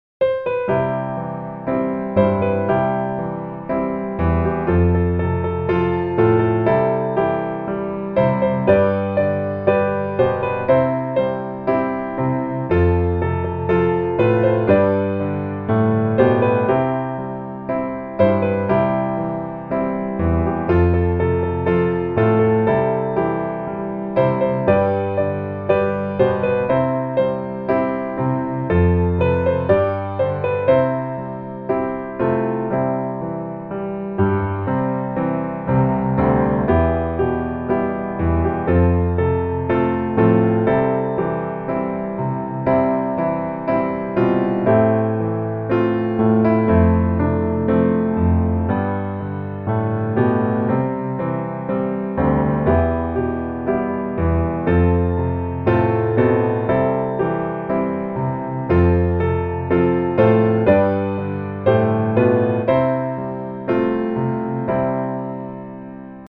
C Major